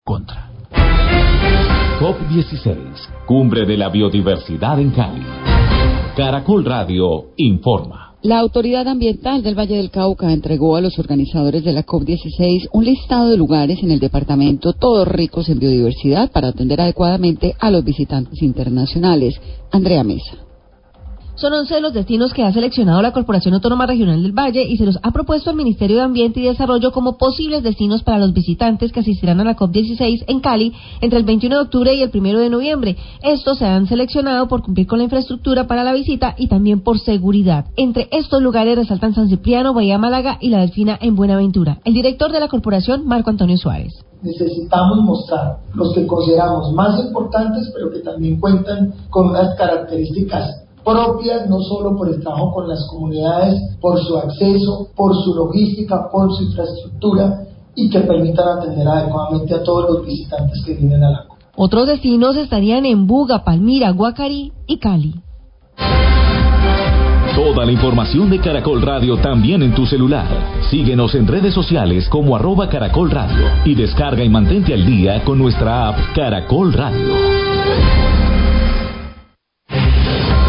Radio
Marco Antonio Suárez, director general de la CVC, habla de la lista de destinos ambientales presentados al Ministerio de Ambiente y los organizadores de la COP16 para que sean visitados por los asistentes a la conferencia.